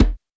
Percs
PERC.11.NEPT.wav